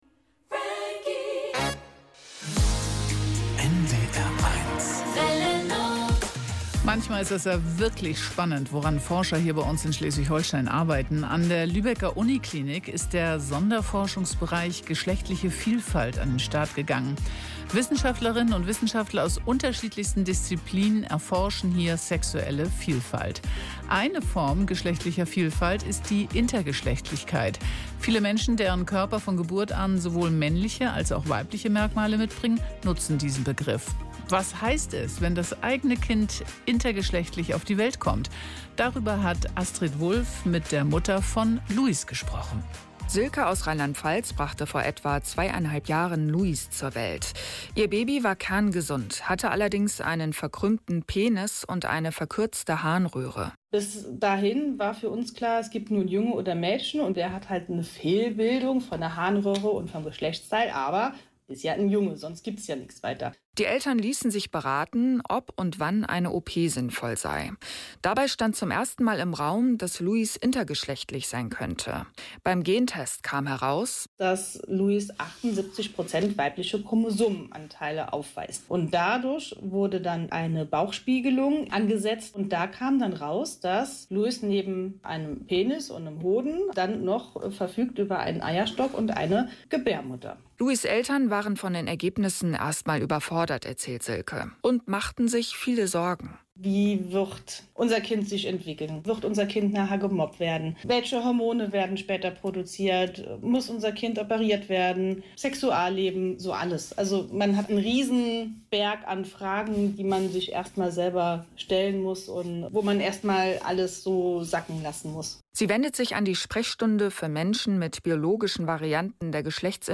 Radiobeitrag
Interview